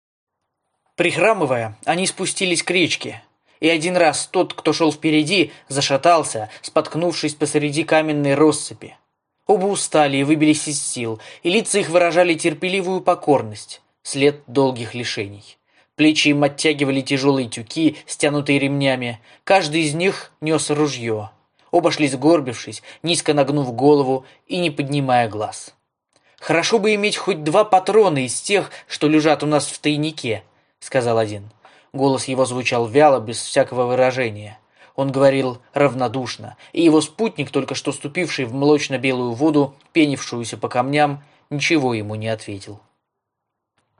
голос.mp3
Мужской